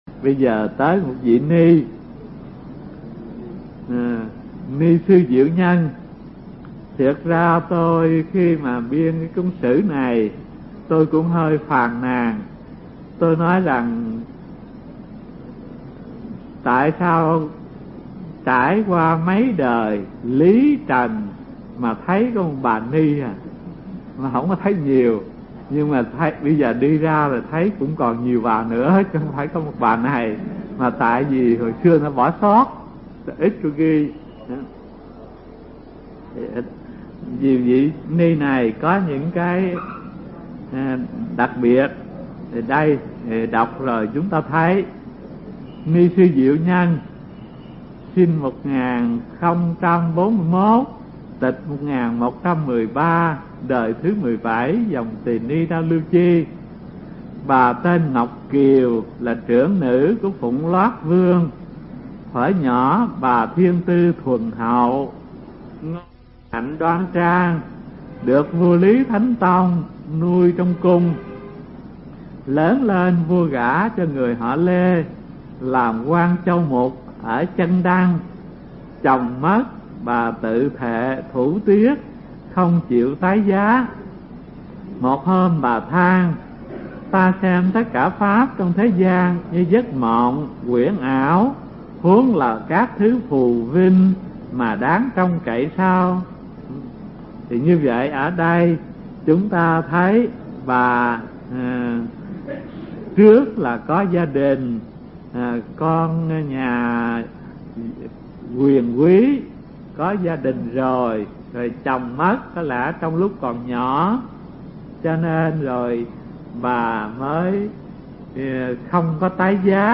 Mp3 Pháp Thoại Thiền Học Phật Giáo Việt Nam 93 – Ni Sư Diệu Nhân (Đời 17 Dòng Tỳ Ni Đa Lưu Chi) thuộc bộ Thiền Học Phật Giáo Việt Nam do Hòa Thượng Thích Thanh Từ giảng tại trường Cao Cấp Phật Học Vạn Hạnh, từ năm 1989 đến năm 1991